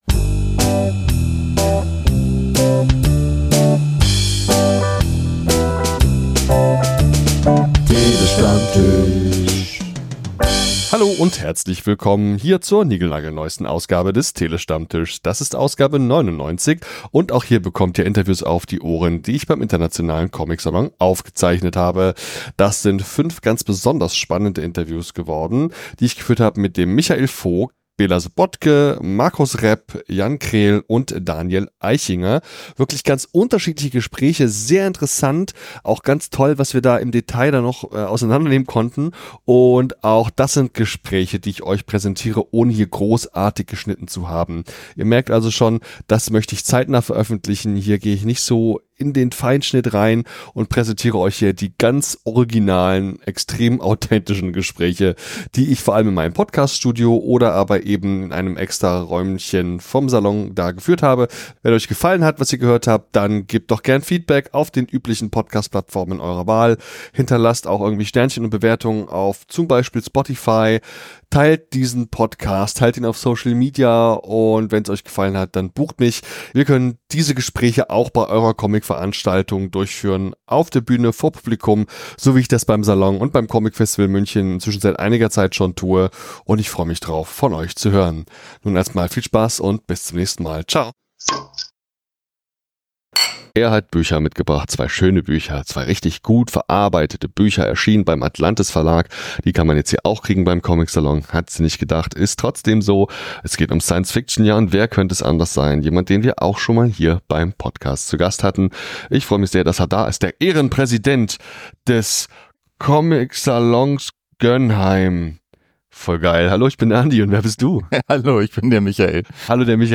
Ich habe vor Ort sehr viel gearbeitet und viele Stunden lang Interviews geführt und aufgezeichnet.